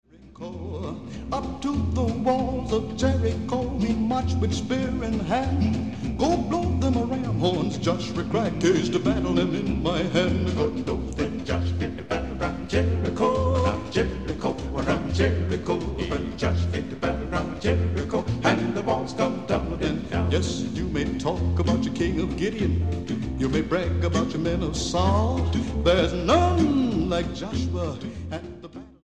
pioneering gospel group